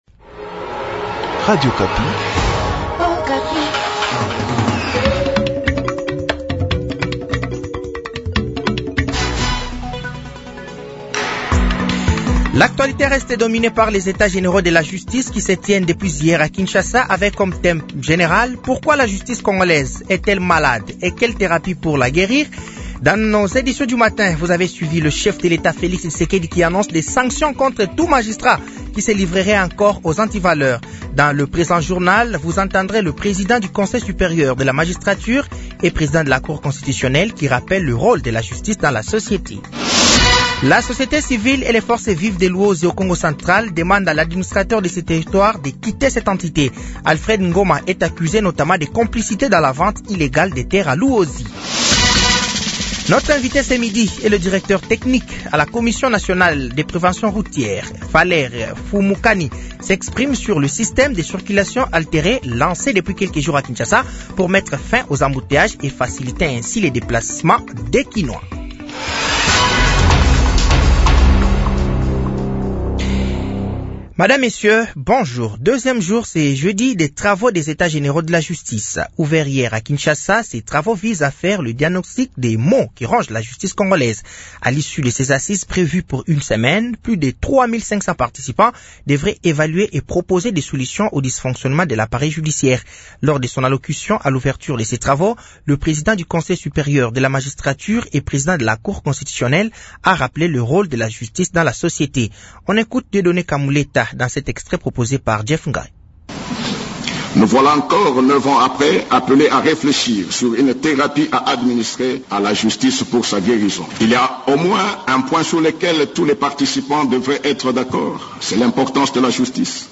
Journal français de 12h de ce jeudi 07 novembre 2024